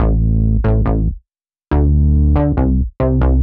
Index of /musicradar/french-house-chillout-samples/140bpm/Instruments
FHC_SulsaBass_140-A.wav